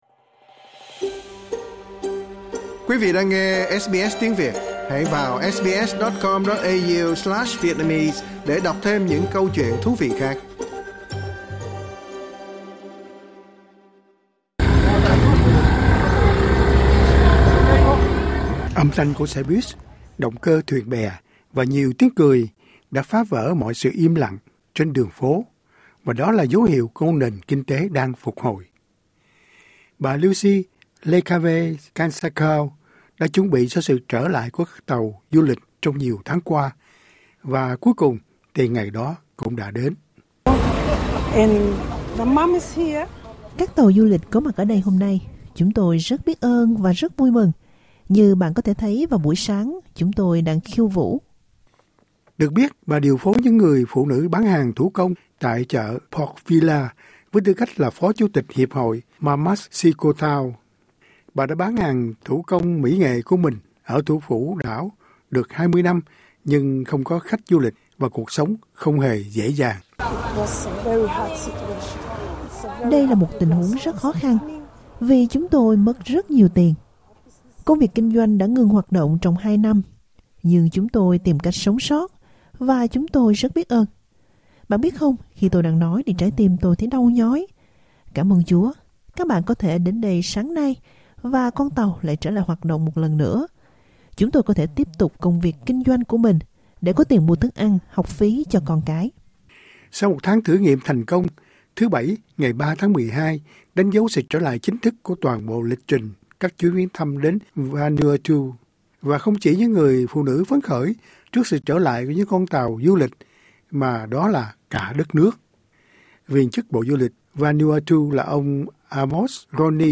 Âm thanh của xe buýt, động cơ thuyền bè và nhiều tiếng cười đã phá vỡ mọi sự im lặng trên đường phố và đó là dấu hiệu của một nền kinh tế đang phục hồi.